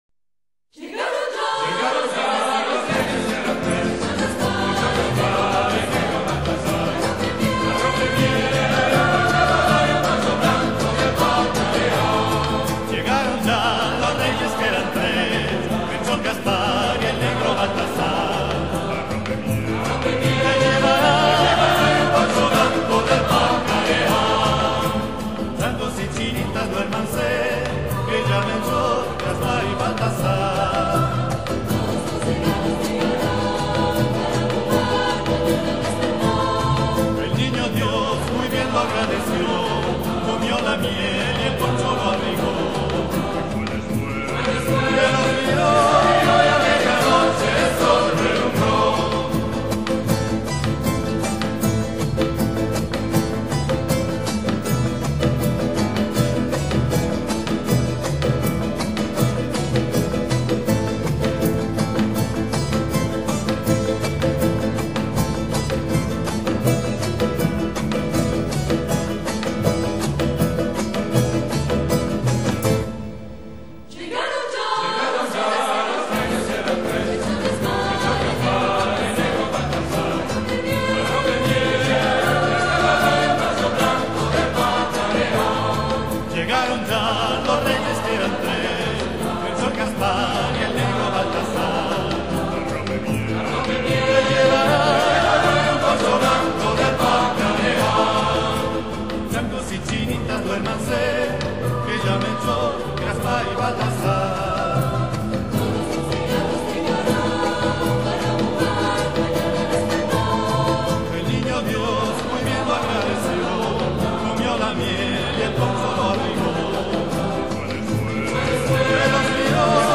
SAGGIO DI NATALE 2002
canto argentino
*** (i re magi ) di Ariel Ramirez riduzione a 2 voci pari, flauti, tastiere chitarra